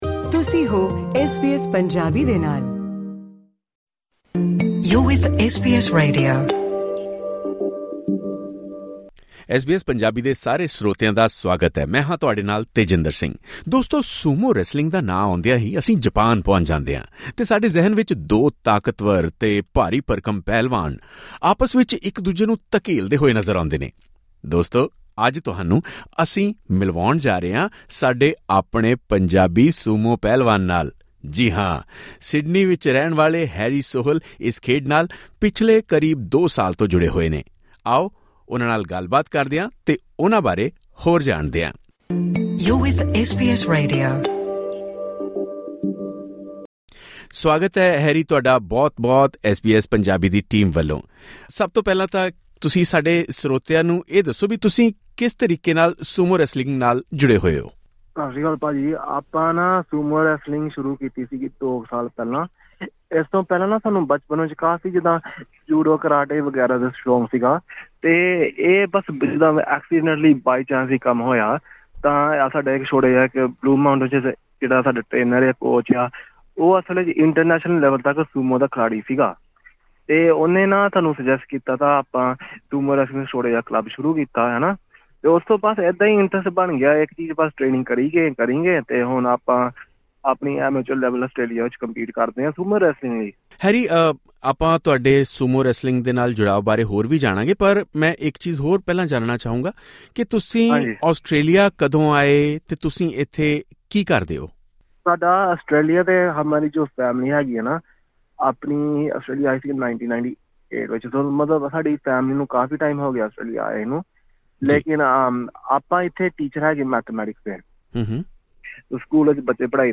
ਉਹਨਾਂ ਨਾਲ ਪੂਰੀ ਗੱਲਬਾਤ ਇਸ ਪੌਡਕਾਸਟ ਰਾਹੀਂ ਸੁਣੋ।